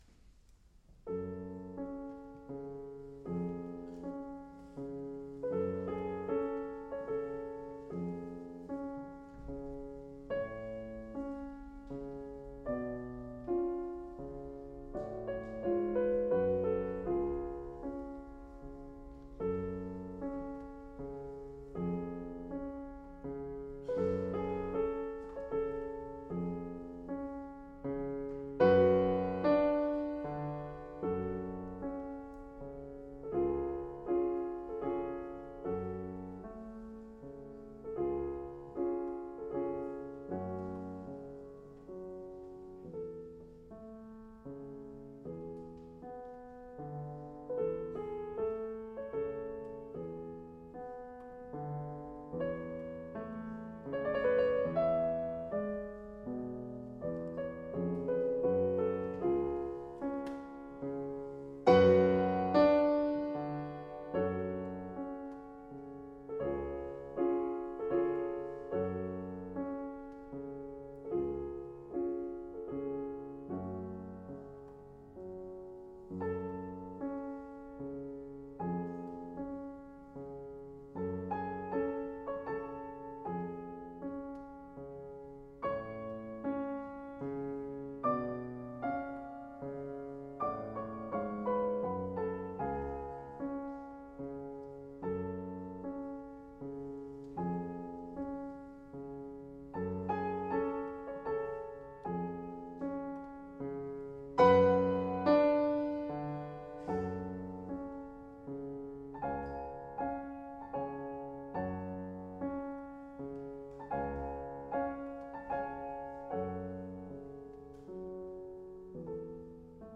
pianestival/Jour 1/2